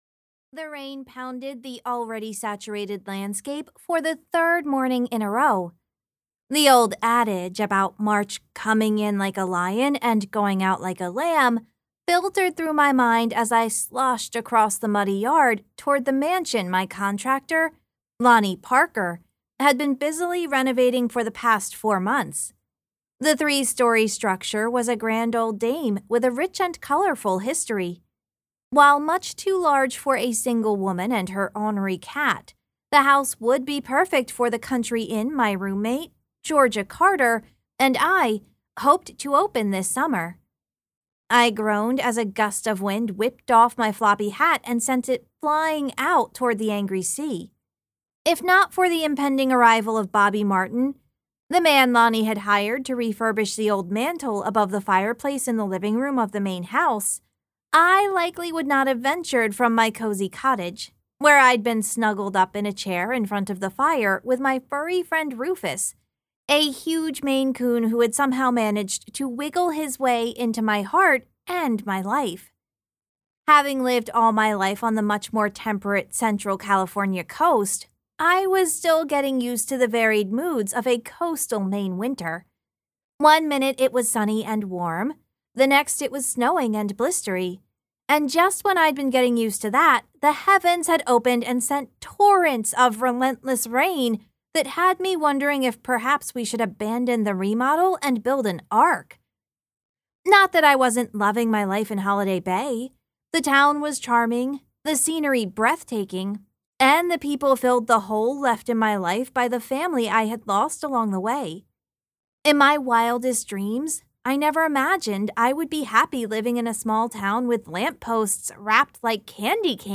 • Audiobook
Book 3 Retail Audio SampleThe Inn at Holiday Bay Message in the Mantel.mp3